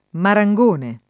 vai all'elenco alfabetico delle voci ingrandisci il carattere 100% rimpicciolisci il carattere stampa invia tramite posta elettronica codividi su Facebook marangone [ mara jg1 ne ] s. m. («uccello»; settentr. «falegname») — sim. i cogn.